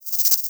edm-perc-38.wav